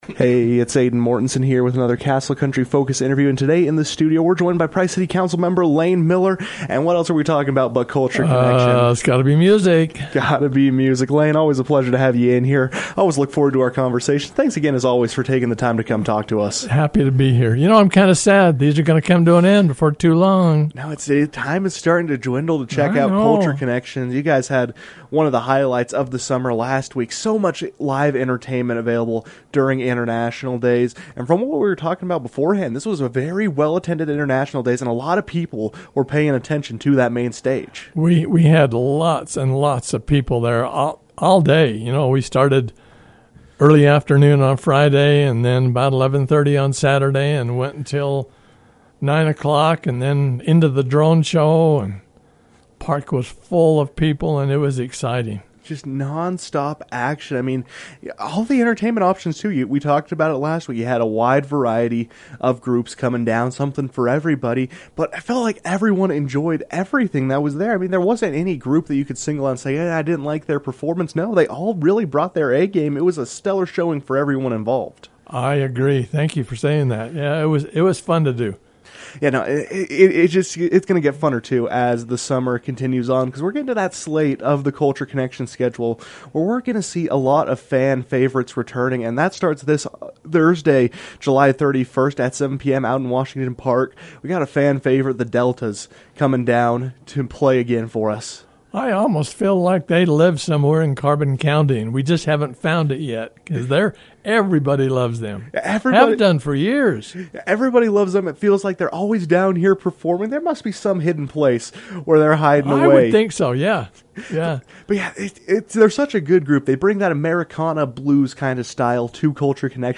Price City Council member Layne Miller joined the KOAL newsroom to discuss the group’s return to Price, as well as reflect on the success of the International Days slate of entertainment.